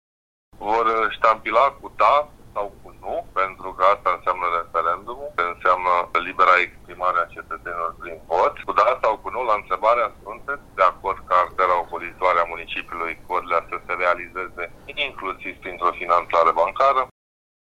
Primarul municipiului Codlea, Mihai Câmpeanu: